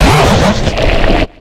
Cri de Bouldeneu dans Pokémon X et Y.